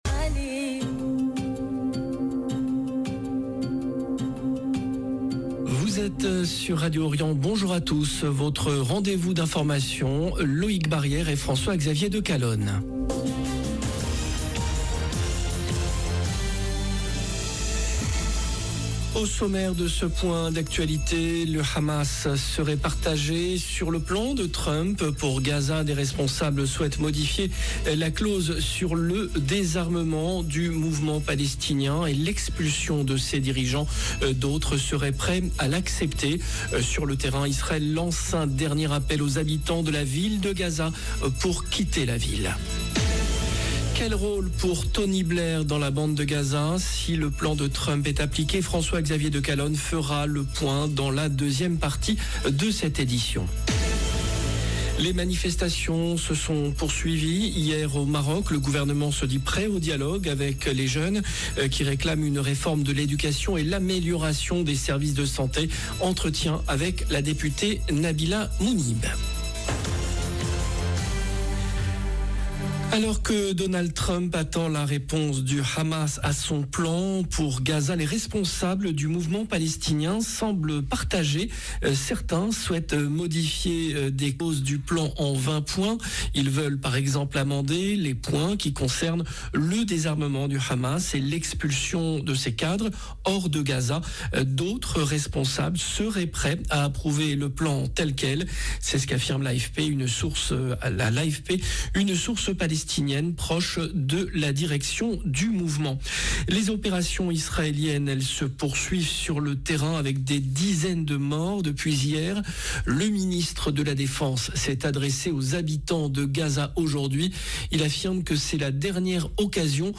Entretien avec la députée Nabila Mounib. 0:00 8 min 29 sec